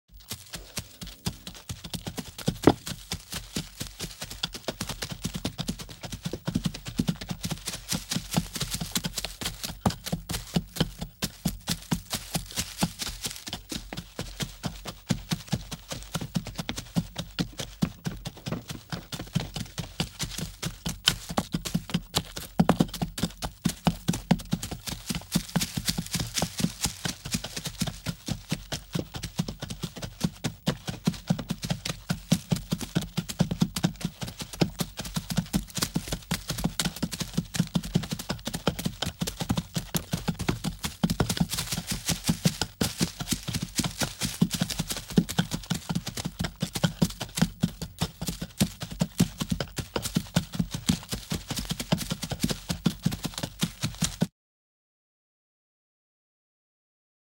دانلود آهنگ گورخر 4 از افکت صوتی انسان و موجودات زنده
دانلود صدای گور خر 4 از ساعد نیوز با لینک مستقیم و کیفیت بالا
جلوه های صوتی